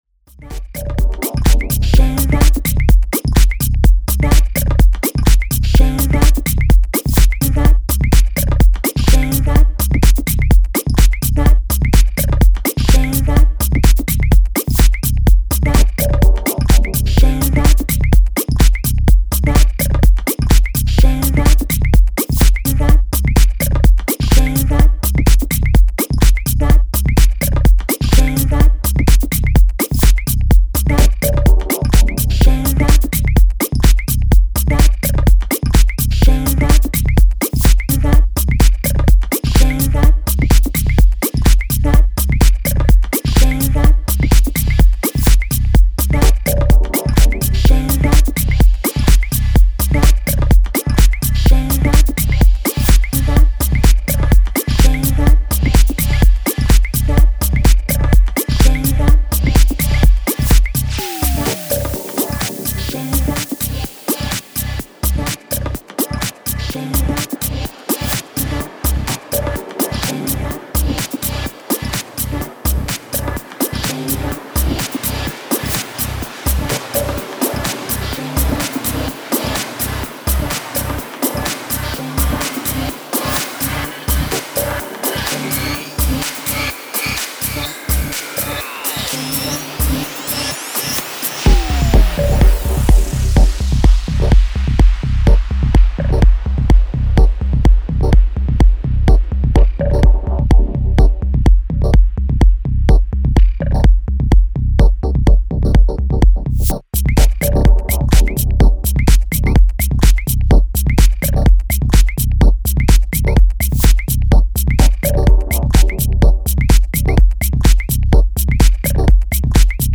Style: House / Tech House